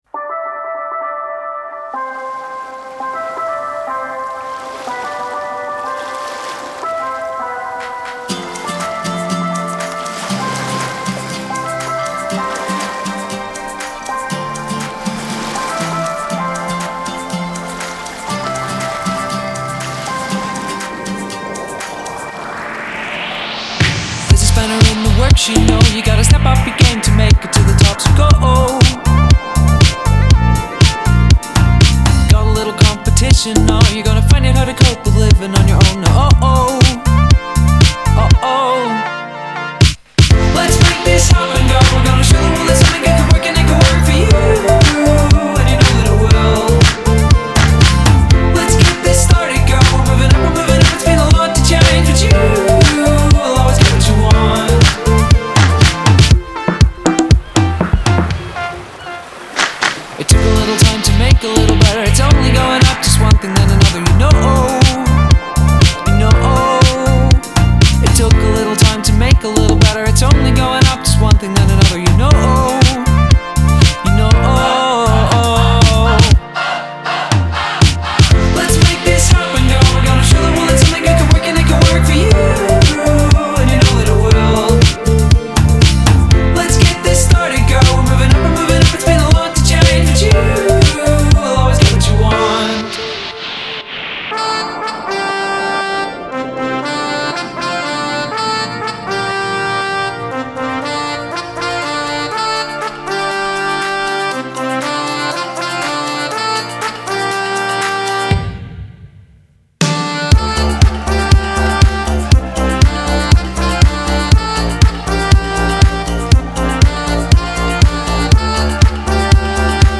The genre I listen to most besides electronic is indie.